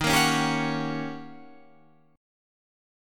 Eb7#9 chord